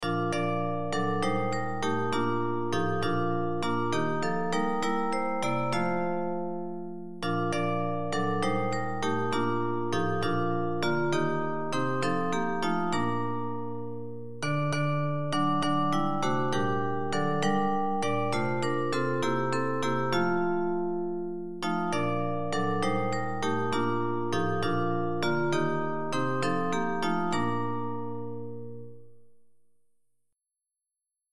Christmas hymns